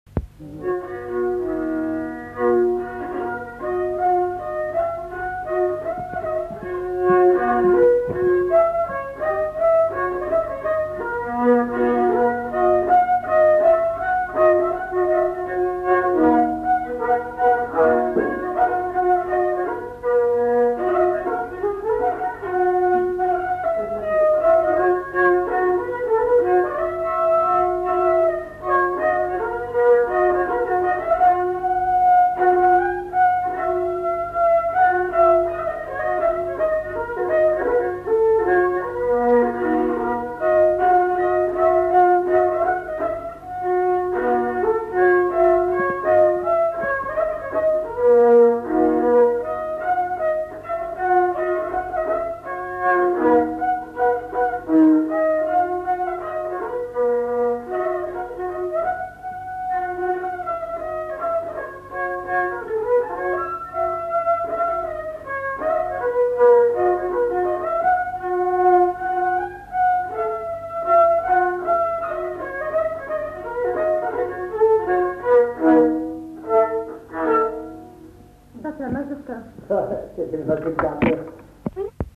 Aire culturelle : Marsan
Lieu : Haut-Mauco
Genre : morceau instrumental
Instrument de musique : violon
Danse : mazurka